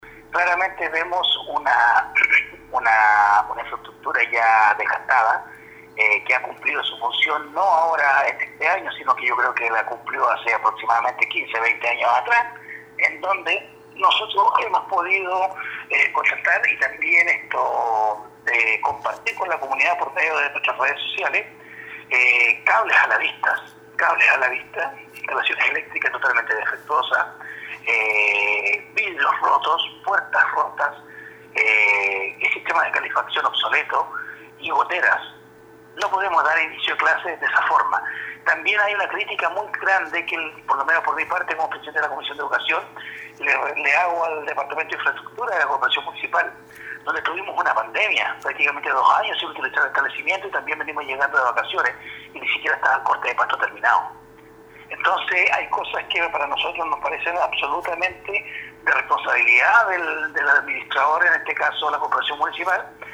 04-CONCEJAL-ANDRES-IBANEZ-3.mp3